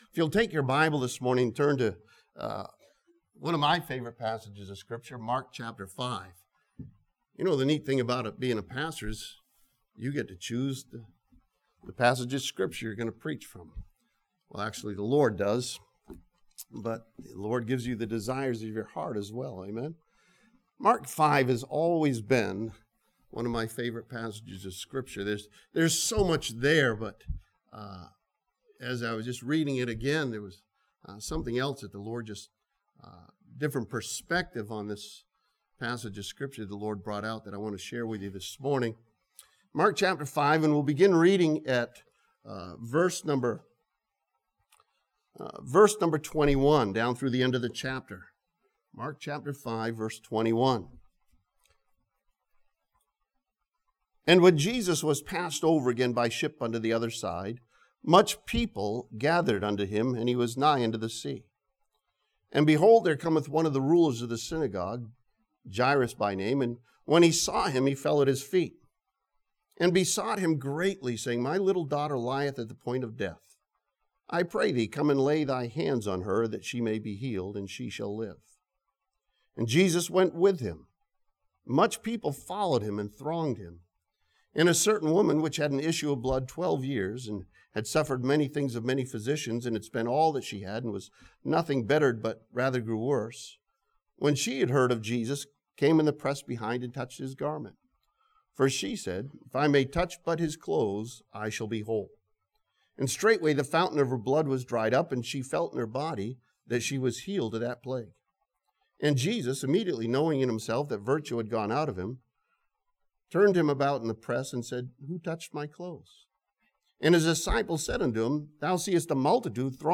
This sermon from Mark chapter 5 studies the stories of people's lives intersecting with Jesus and His influence on them.